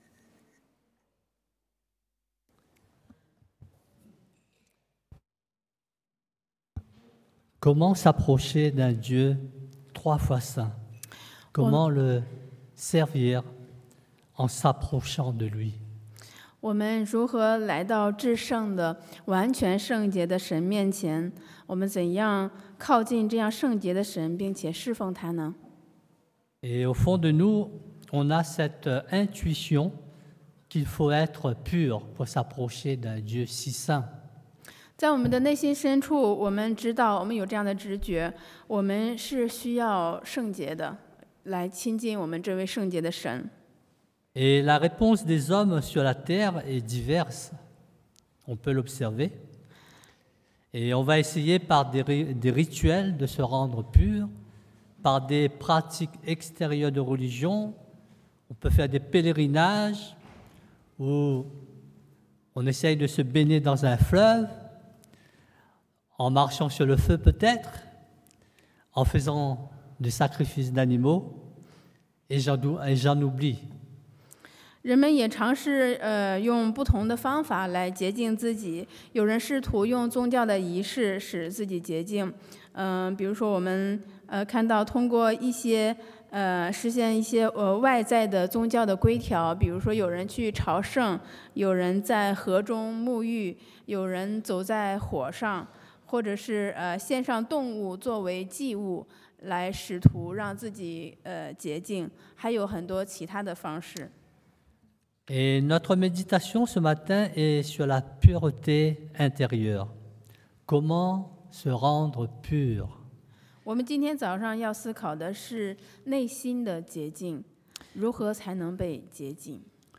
Predication du dimanche « Homme de peu de foi